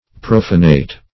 Profanate \Prof"a*nate\